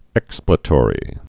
(ĕksplĭ-tôrē)